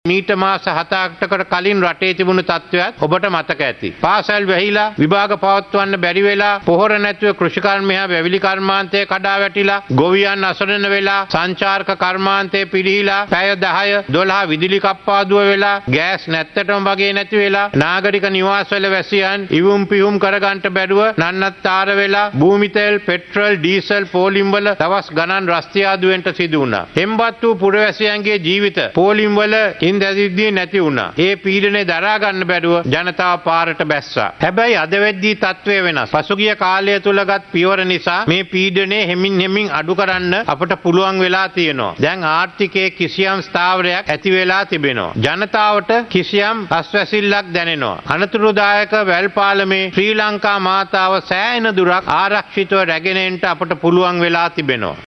ඒ අනුව ජනාධිපති රනිල් වික්‍රමසිංහ මහතා විසින් නව වන පාර්ලිමේන්තුවේ සිව් වන සභා වාරය ආරම්භ කරමින් ආණ්ඩුවේ ප්‍රතිපත්ති ප්‍රකාශය ඉදිරිපත් කරනු ලැබුවා.